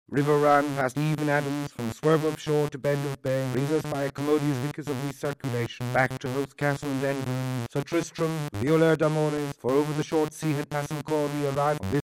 pyin-test.mp3